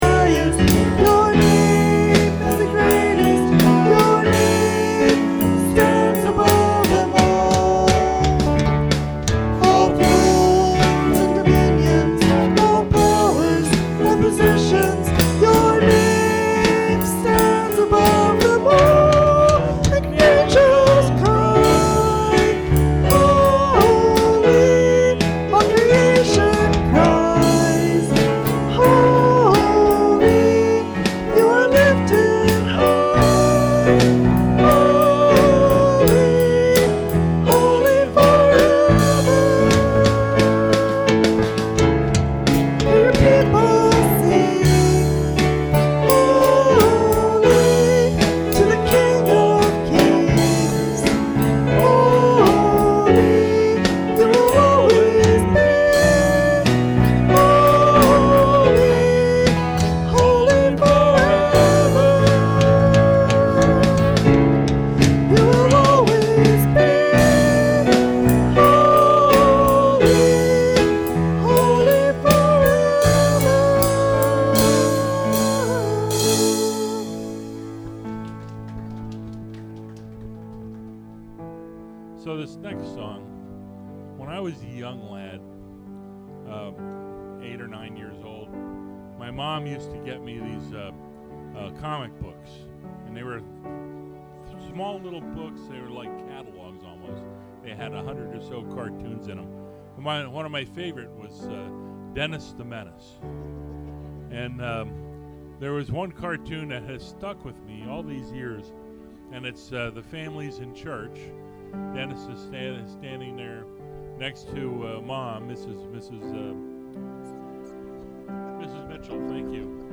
Podcast (sermons): Play in new window | Download
Series: Sunday Morning Worship Service